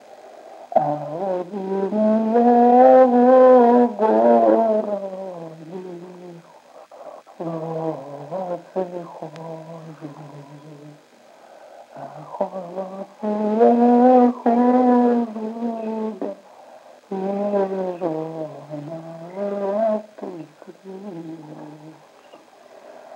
Упрощение консонансов с выпадением одного из элементов консонанса